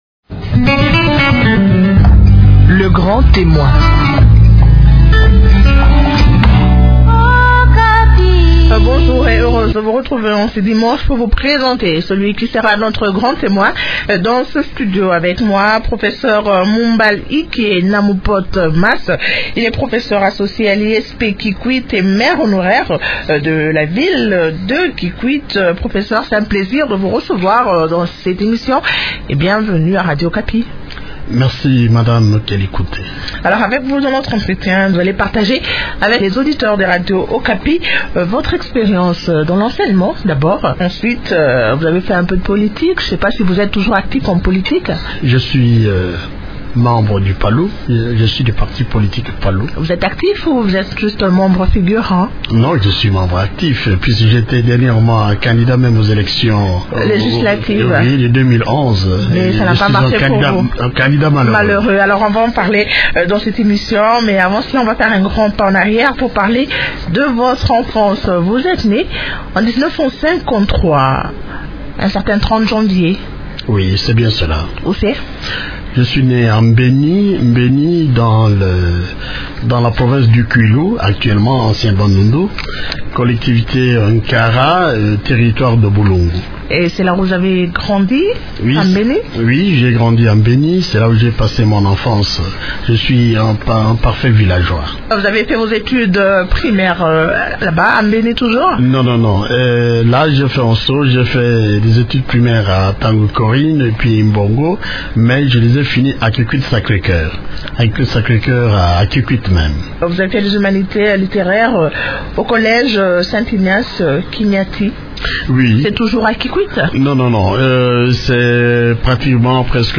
Mumbal Ike Namupot Mas, professeur d’université, maire honoraire de Kikwit et cadre du Parti lumumbiste unifié, lors de son passage le 15 décembre 2015 au siège de Radio Okapi.